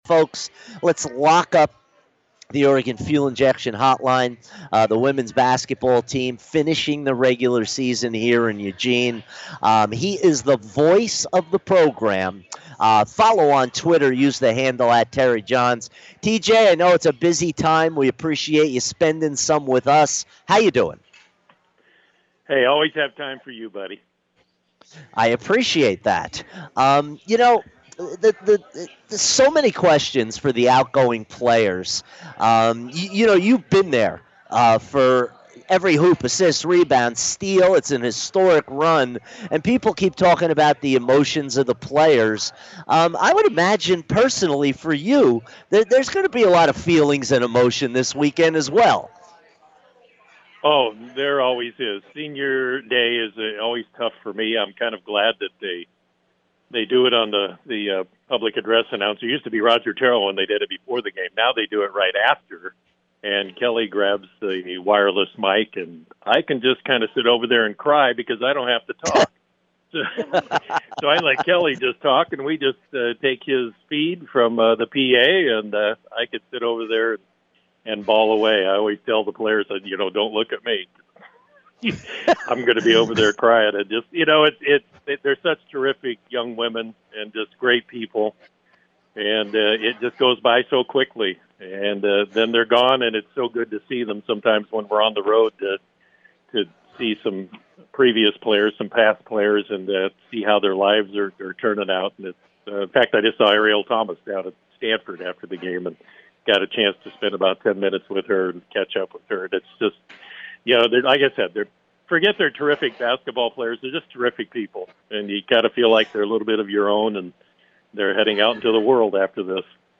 Sports Talk